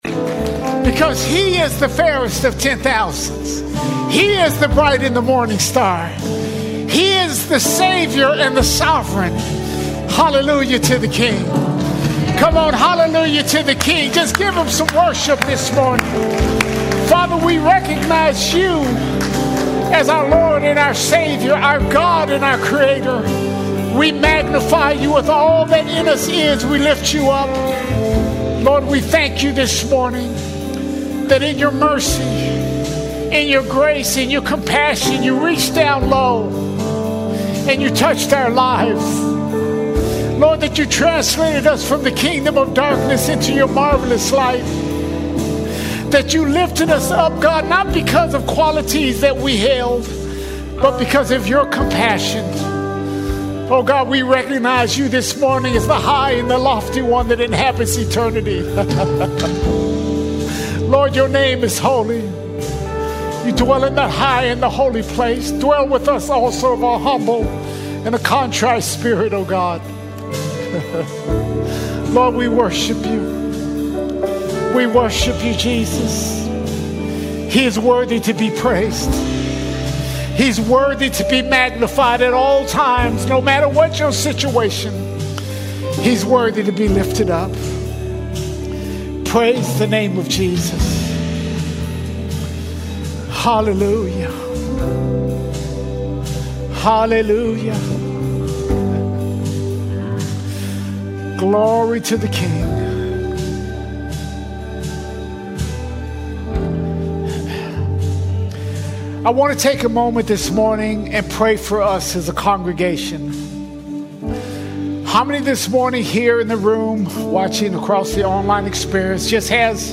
23 March 2026 Series: Sunday Sermons All Sermons A World Under Conviction A World Under Conviction The world isn’t just watching—it’s being convicted.